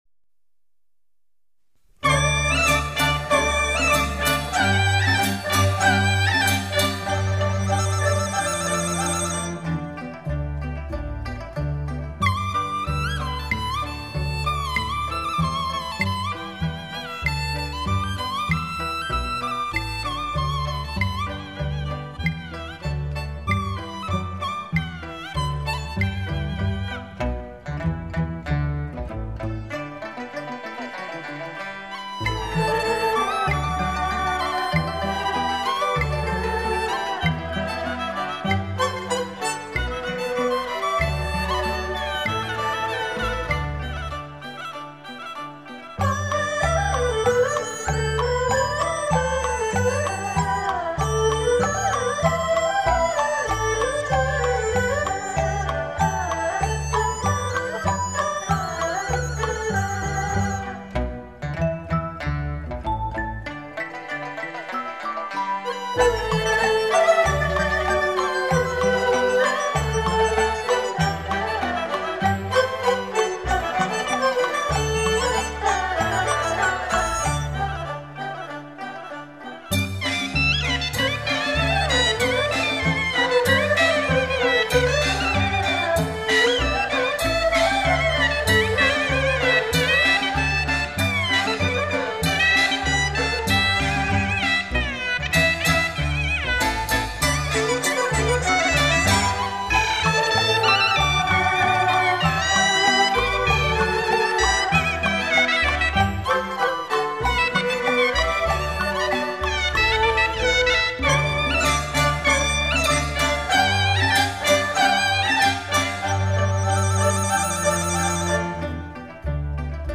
喜庆民乐是中国民乐的一个大支流，带有极强的世俗音乐色彩，长于表达喧哗热闹的场面，
渲染喜庆气氛，通常以唢呐、锣、鼓等豪放，刚劲富有表达力的乐器为主要表达方式。